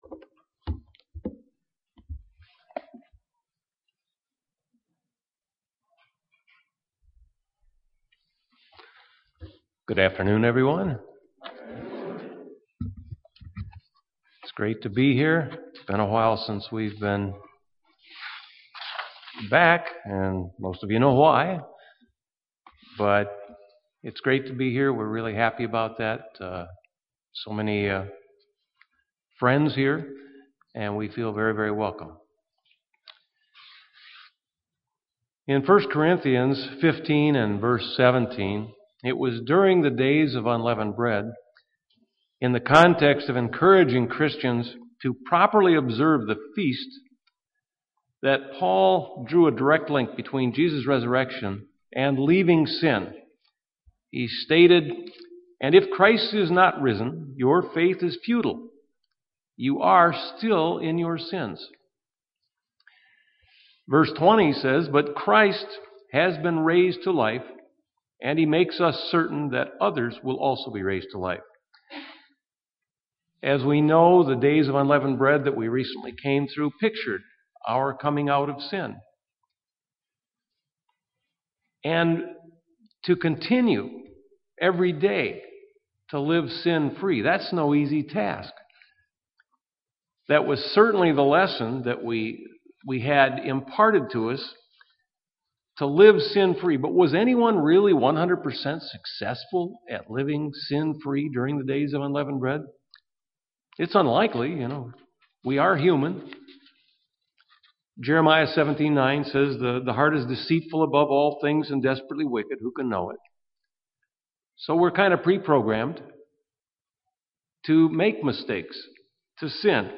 Given in Milwaukee, WI
Print Some aspects of faith UCG Sermon